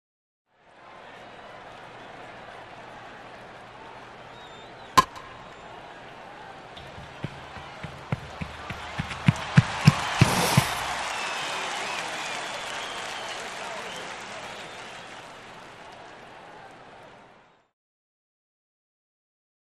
Pitch/Hit/Crowd | Sneak On The Lot
Baseball Pitch / Hit / Run / Slide: with Crowd Reaction; Crowd At A Baseball Game; Bat Hitting Ball In Foreground, Crowd Swells, Batter Runs, Crowd Cheers, Batter Slides, Close Perspective.